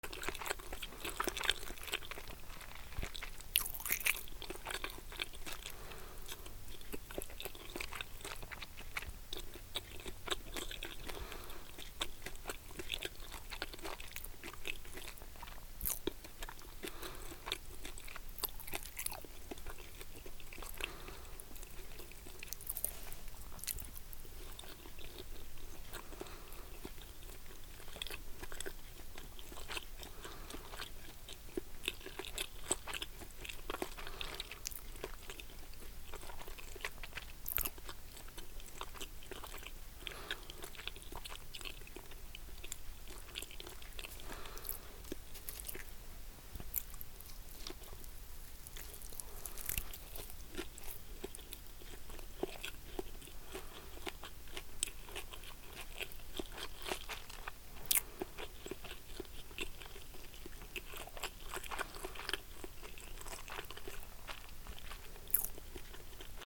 食べる そしゃく
『グチャグチャ』